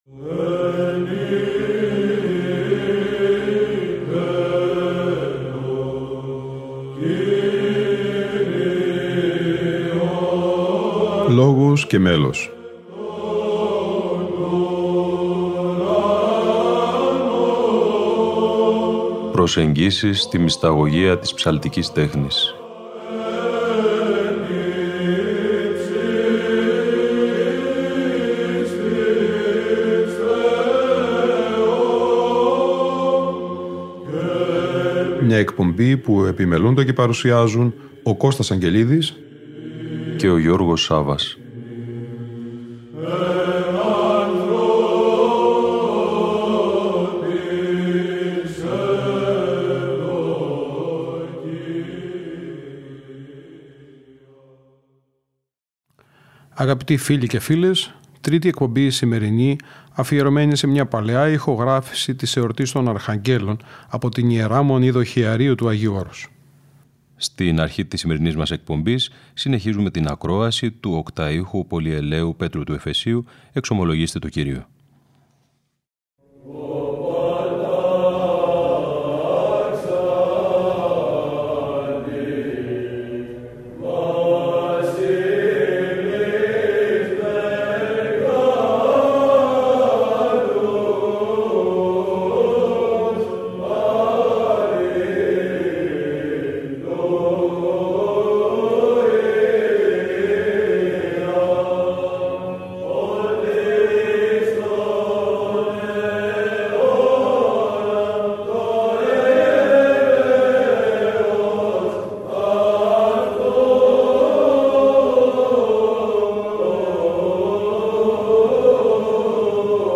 Αγρυπνία Αρχαγγέλων στην Ι.Μ. Δοχειαρίου (Γ΄)